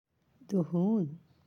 (duhūn)